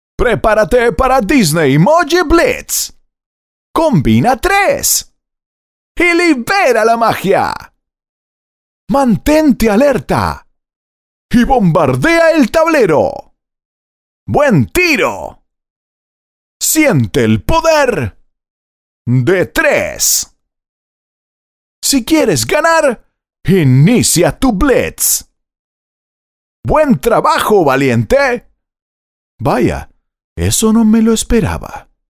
Male
Spanish (Latin American), Spanish (Argentina)
Adult (30-50)
Video Games
All our voice actors have professional broadcast quality recording studios.